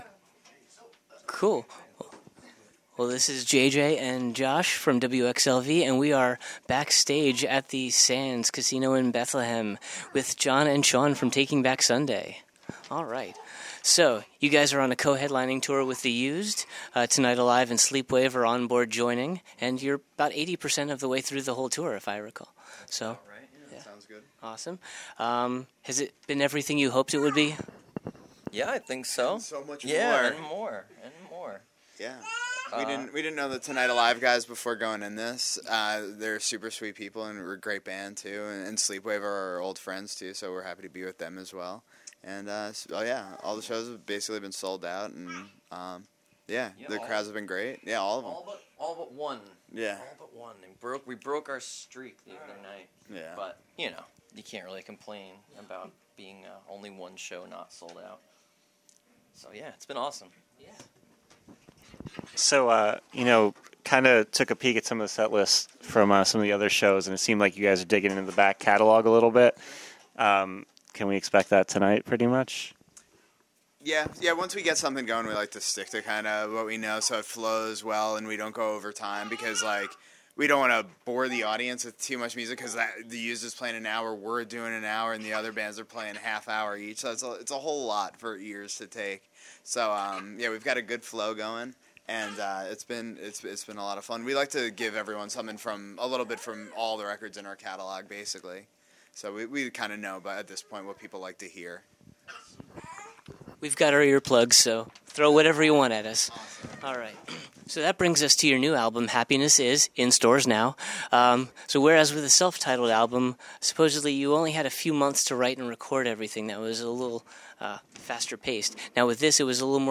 Right before the gates opened and a flood of rabid fans filled the Sands Event Center to the SOLD OUT show for the evening, we got the chance to talk to John Nolan and Shuan Cooper of Taking Back Sunday (who also happened to be half of Straylight Run which is currently in indefinite hiatus). We talked about the tour’s selling out streak, their current release Happiness Is , the recording process and the production, the album artwork, what might be their next single and what’s to come for the rest of the year for the band.
43-interview-taking-back-sunday.mp3